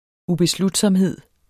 Udtale [ ubeˈsludˌsʌmˀˌheðˀ ]